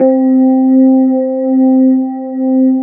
FEND1L  C3-R.wav